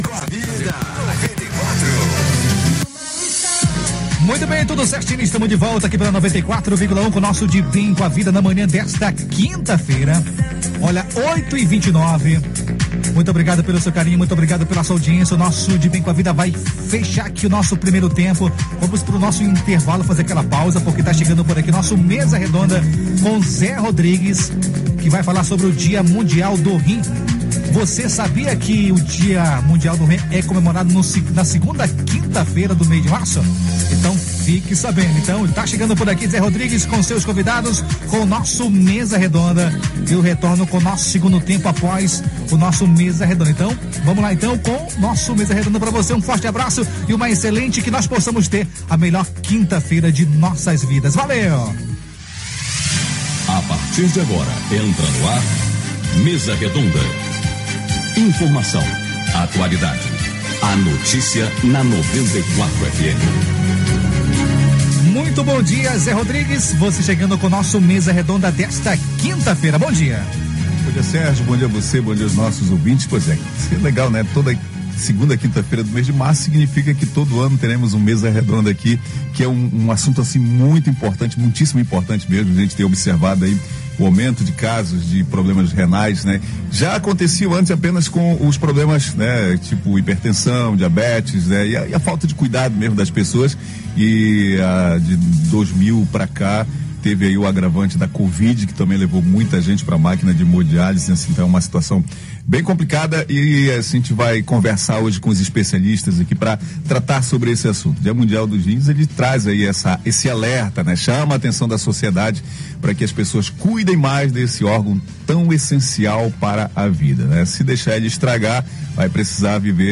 Rádio Tapajós – Mesa Redonda – Dia Mundial do Rim